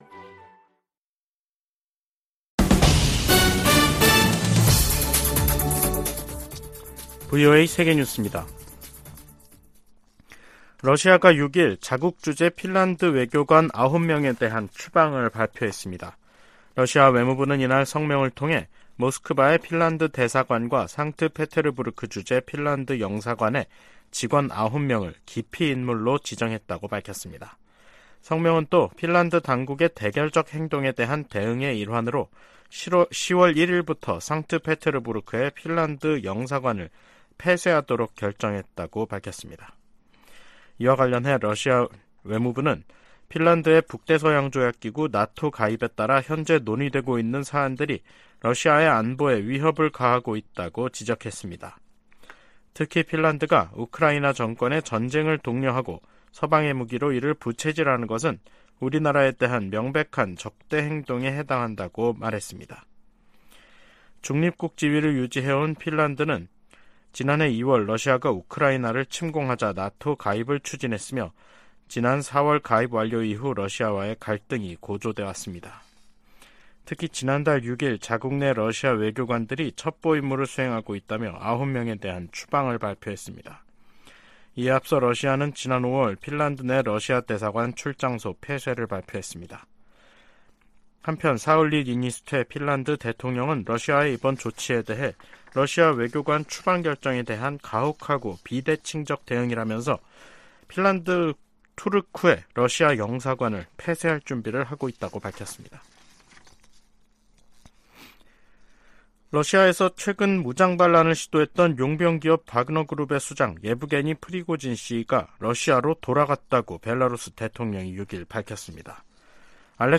VOA 한국어 간판 뉴스 프로그램 '뉴스 투데이', 2023년 7월 6일 3부 방송입니다. 한국 군 당국이 서해에서 인양한 북한의 정찰위성을 분석한 결과 군사적 효용성이 전혀 없다고 평가했습니다. 한중 외교 당국 대화가 재개된 것은 '위험 관리'에 들어간 최근 미중 간 기류와 무관하지 않다고 미국의 전직 관리들이 진단했습니다. 중국의 반간첩법 개정안은 중국 내 탈북민 구출 활동을 봉쇄할 수 있는 악법이라고 탈북 지원단체들과 브로커들이 말했습니다.